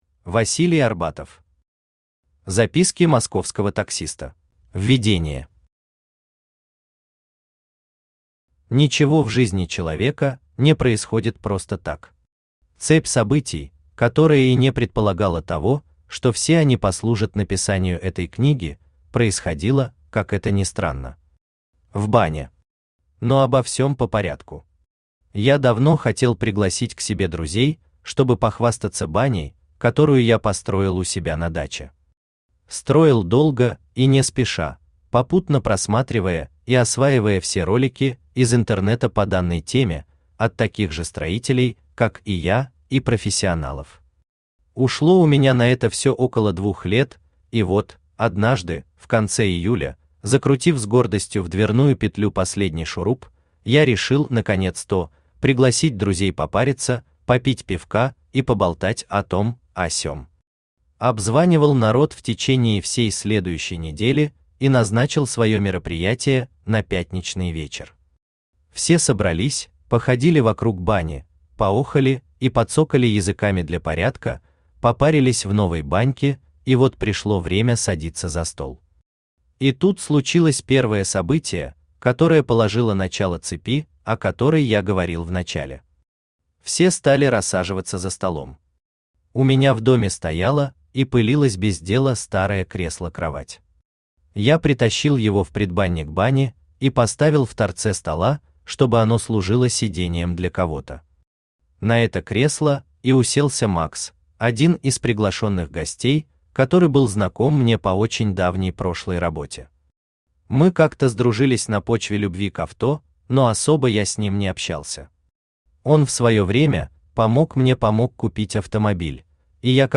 Аудиокнига Записки московского таксиста | Библиотека аудиокниг
Aудиокнига Записки московского таксиста Автор Василий Арбатов Читает аудиокнигу Авточтец ЛитРес.